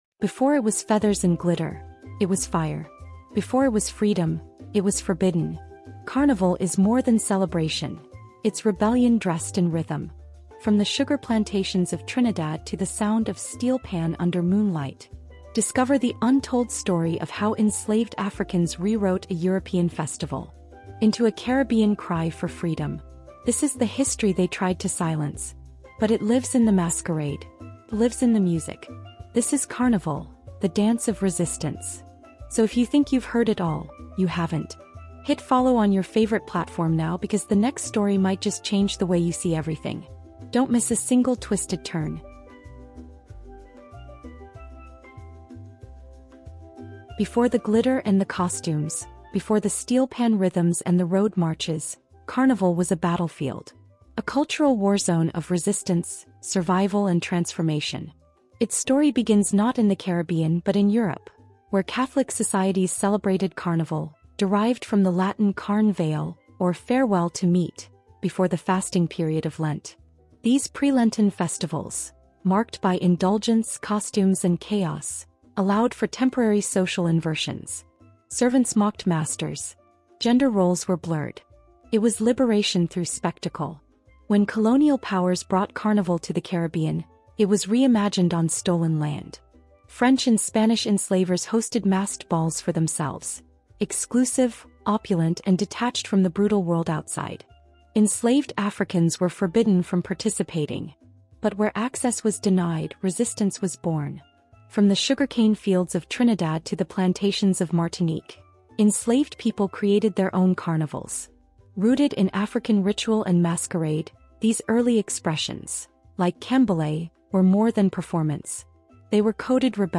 From the forbidden African drums of enslaved ancestors to the explosive rise of Calypso, steelpan, and Soca, Carnival has always been an act of rebellion, heritage, and resilience. Told in 10 chapters with cinematic realism and backed by expert historical storytelling, this documentary explores the Canboulay Riots, colonial criminalization of masquerade, the spiritual symbolism of mas, and the cultural resilience of Caribbean people across generations.